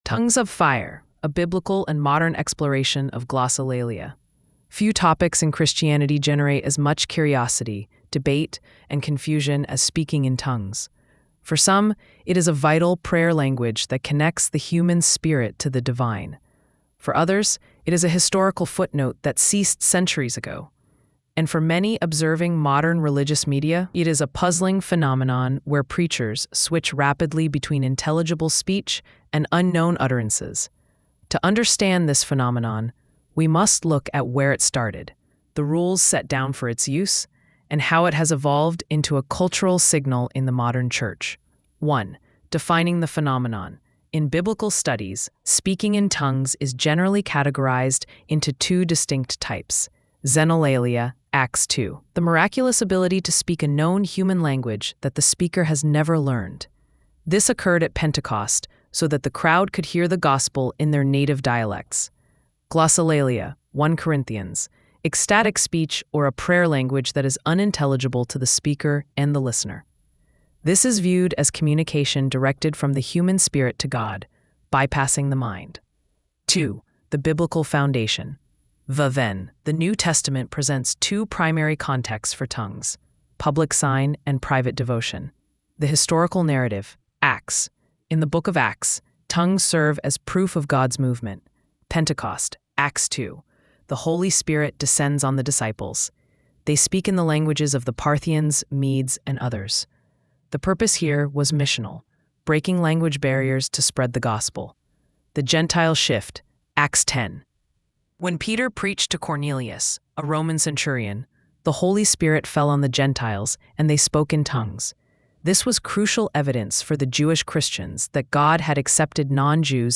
“Whispers of Fire” is a poetic worship anthem inspired by Pentecost, where the apostles received tongues of fire to proclaim the gospel of Christ.
Epic, emotional, and deeply devotional, it invites listeners into the same flame-lit surrender that ignited the Church.